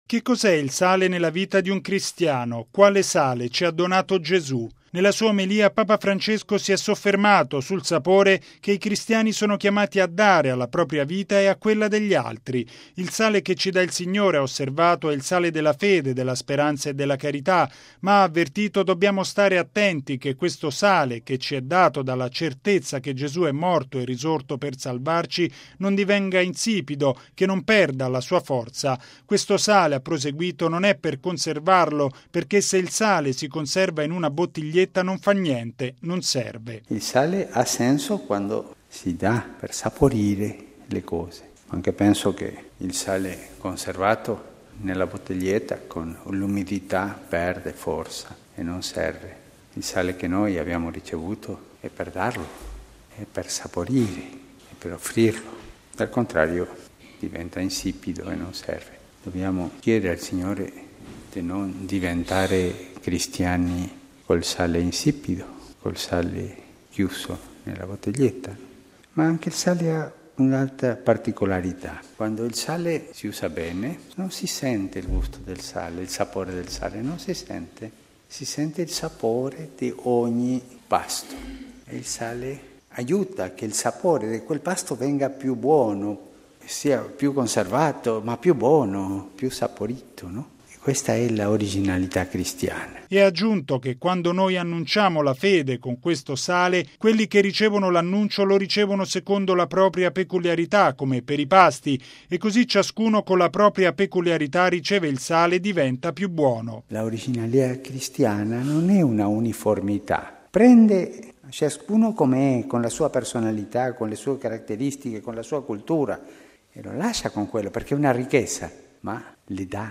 ◊   I cristiani diffondano il sale della fede, della speranza e della carità: è questa l'esortazione di Papa Francesco nella Messa di stamani alla Casa Santa Marta.